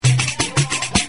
バテリア＆サンバ楽器